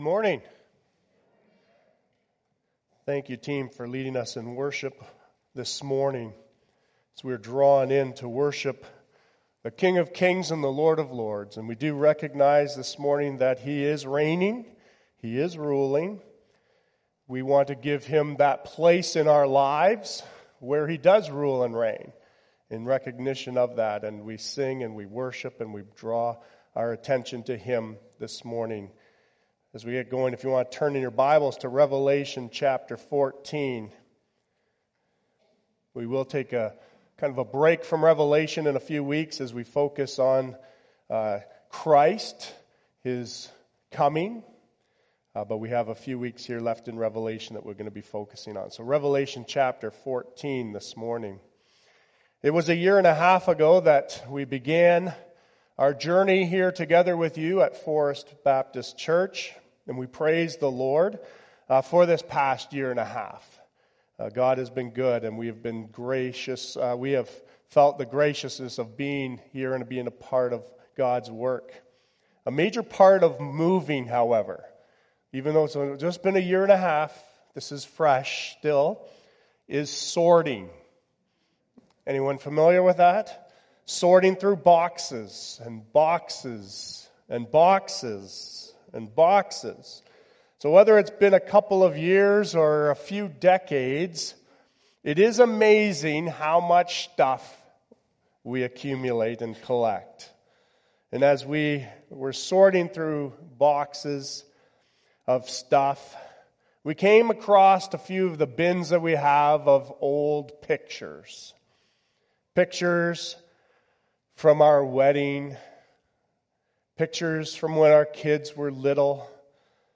Sermons | Forest Baptist Church